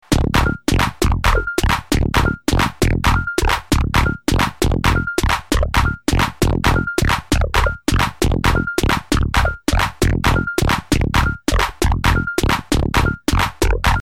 Locked Groove
Techno